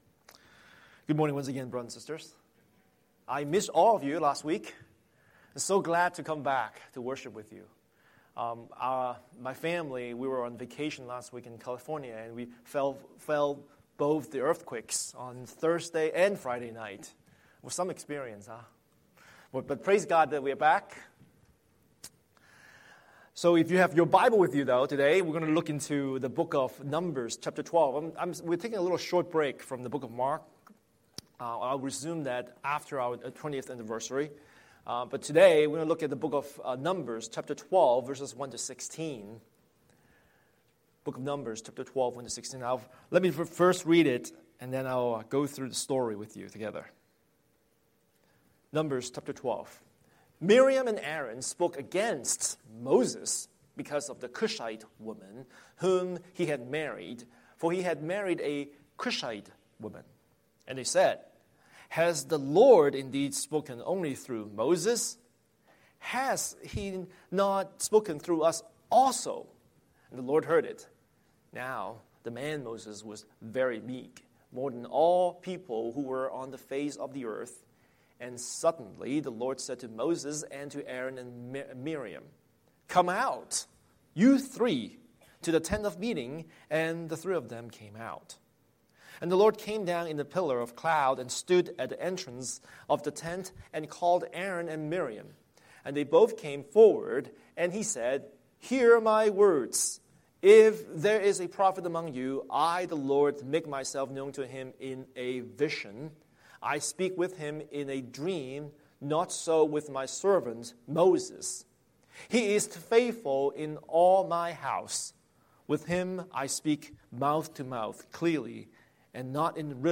Scripture: Numbers 12:1-16 Series: Sunday Sermon